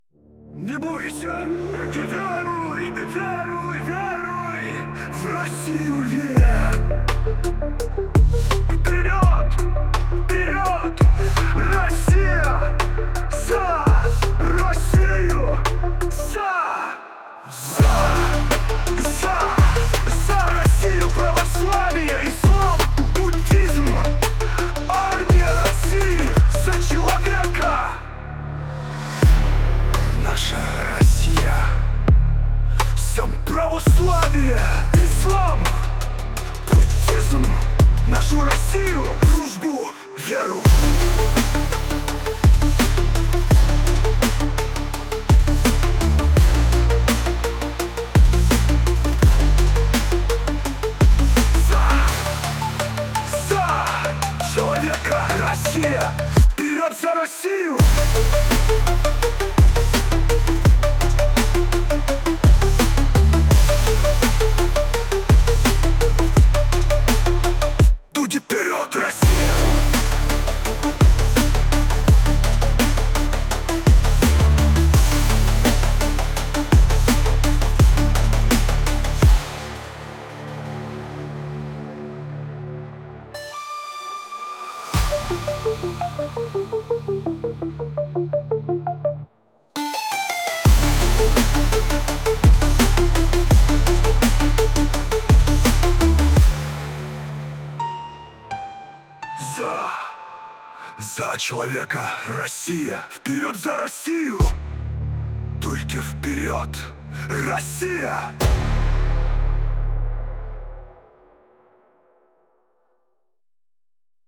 Музыка в стиле техно